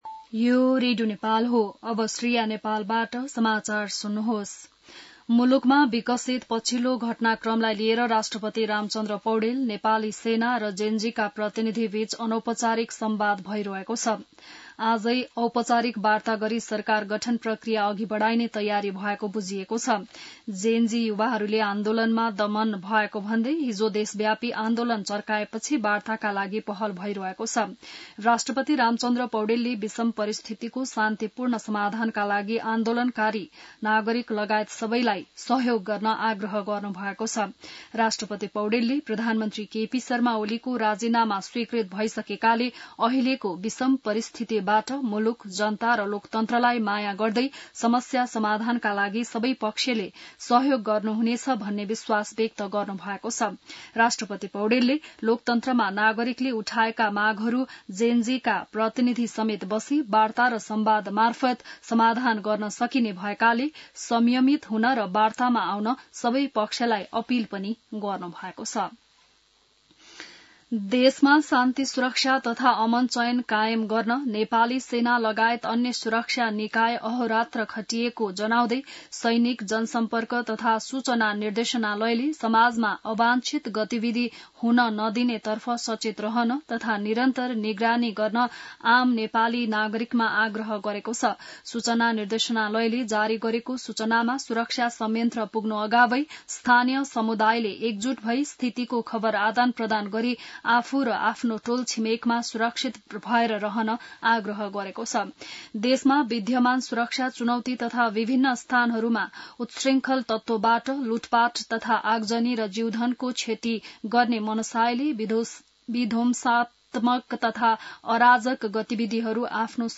बिहान १० बजेको नेपाली समाचार : २५ भदौ , २०८२